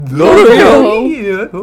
Audio / SE / Cries / DODRIO.mp3